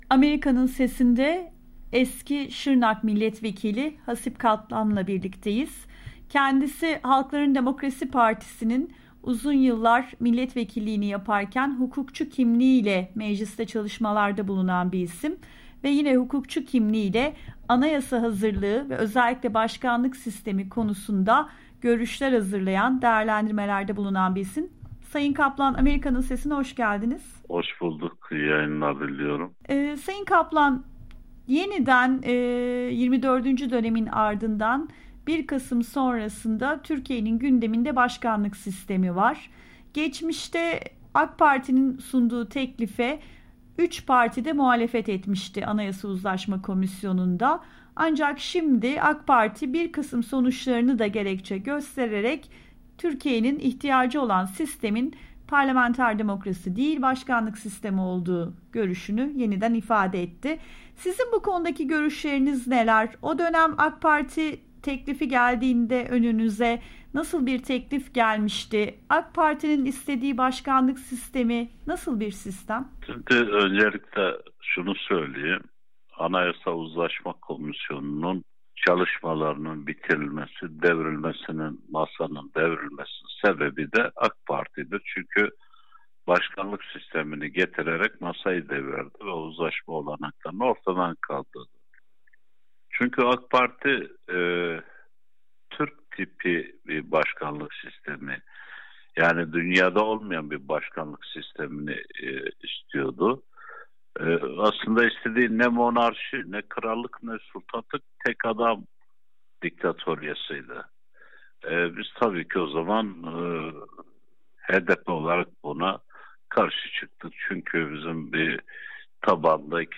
Hasip Kaplan'la söyleşi